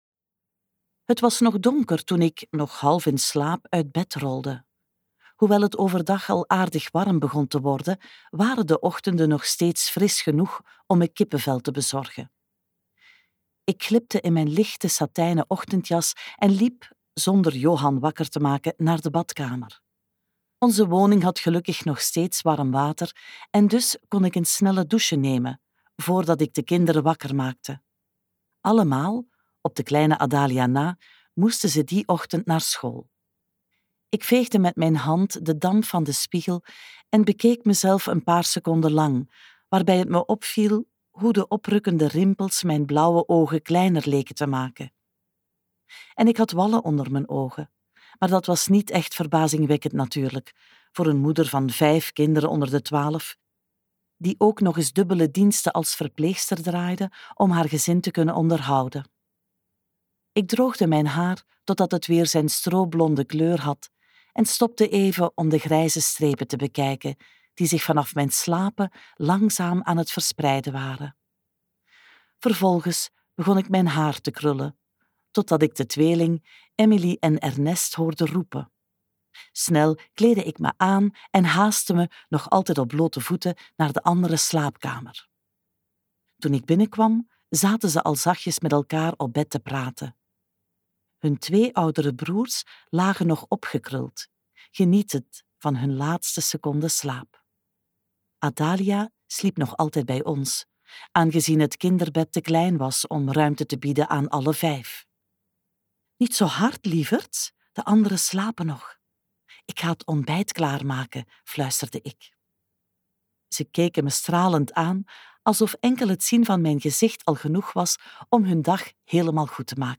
KokBoekencentrum | Het schooltje van auschwitz luisterboek 2